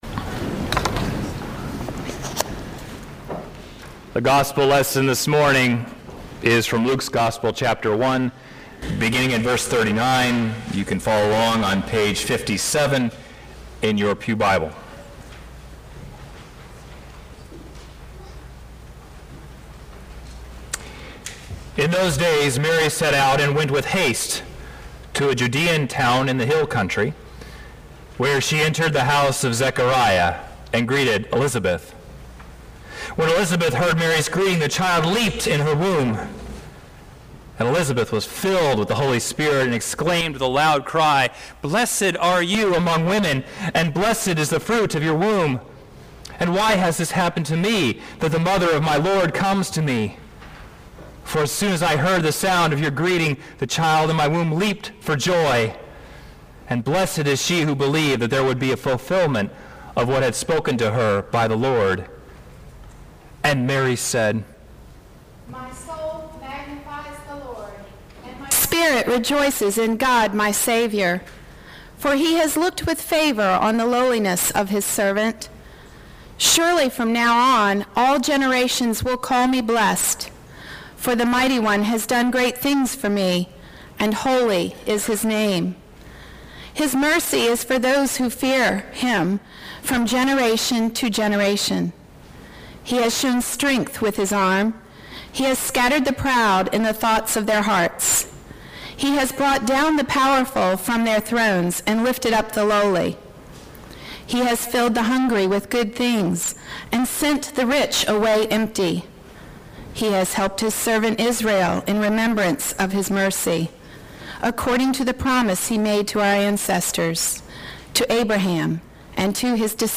sermon-12.11.2.mp3